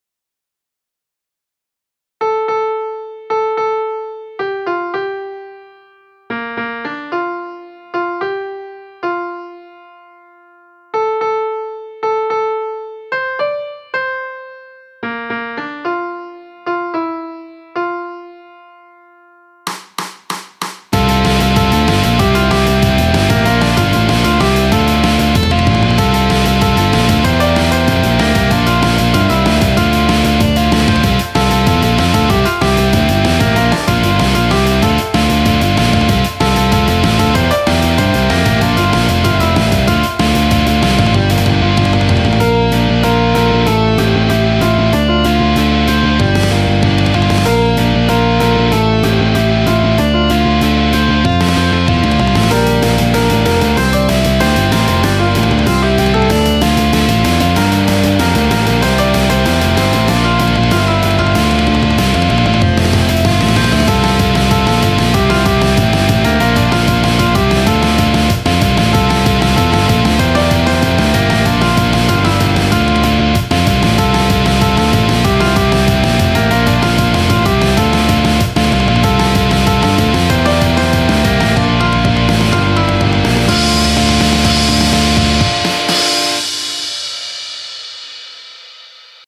BGM
ロング明るい激しい